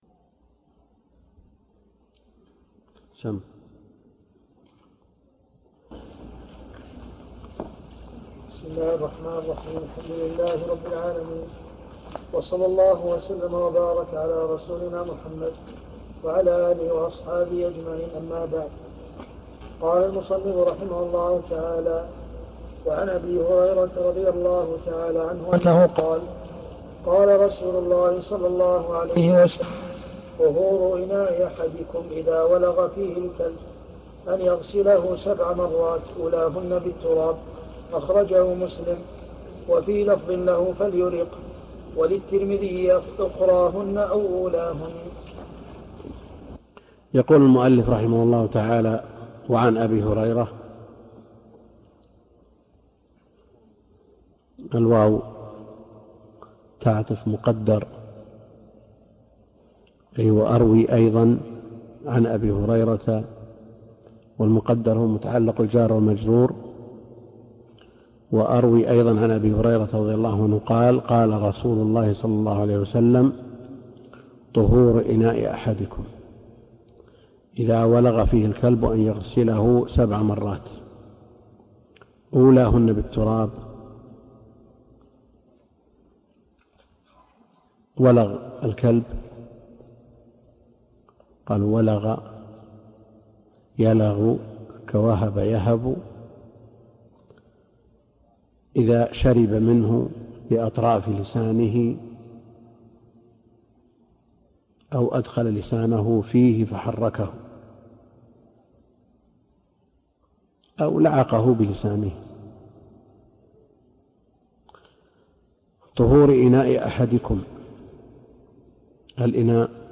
الدرس (3) كتاب الطهارة من بلوغ المرام - الدكتور عبد الكريم الخضير